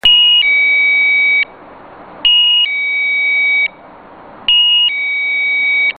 sa sonnette.
porte.mp3